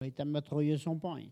Elle provient de Bouin.
Locution ( parler, expression, langue,... )